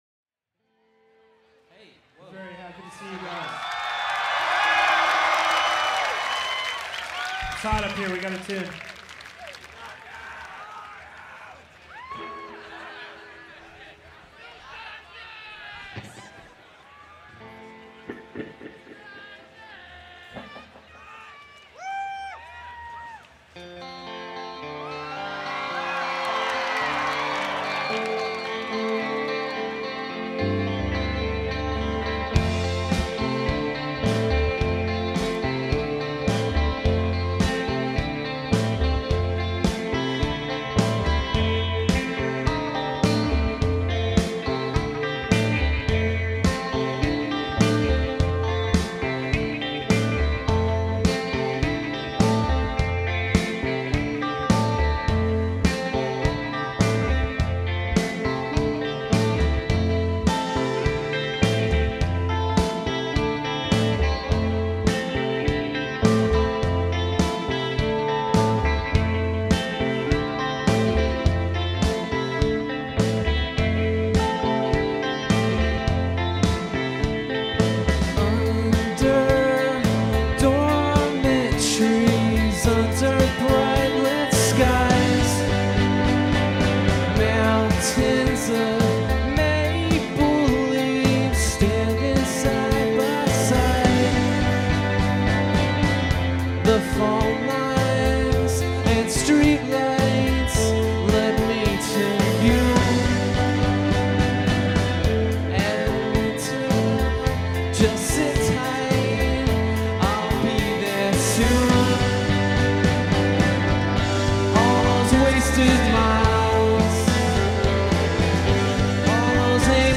recorded live
Americana
vocals, guitar
keyboards
drums